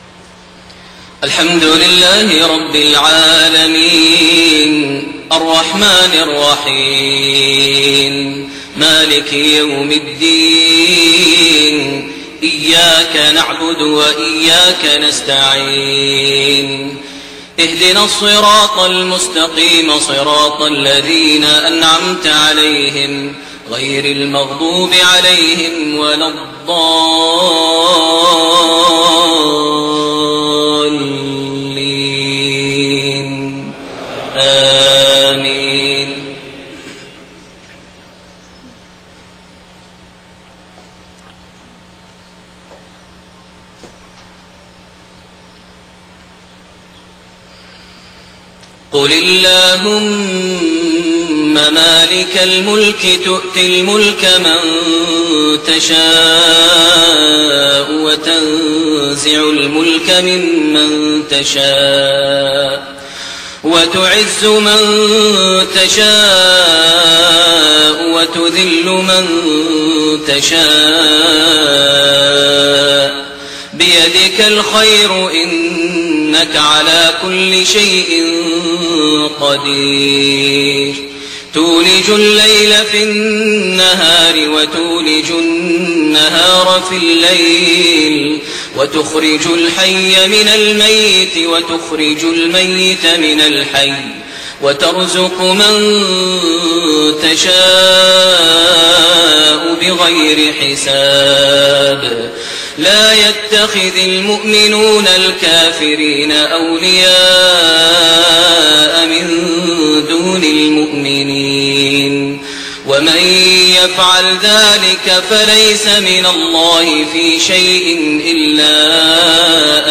صلاة المغرب5-5-1430 من سورة ال عمران26-32 > 1430 هـ > الفروض - تلاوات ماهر المعيقلي